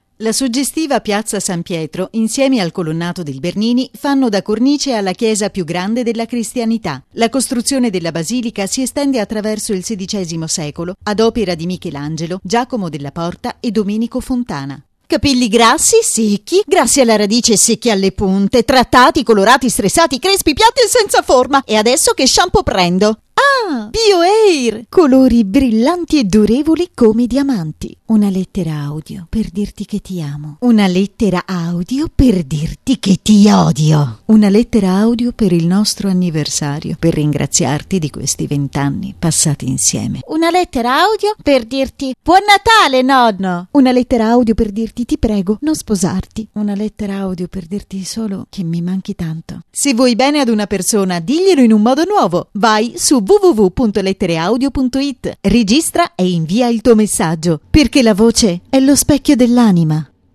voce versatile, calda, istituzionale, ironica
Sprechprobe: Werbung (Muttersprache):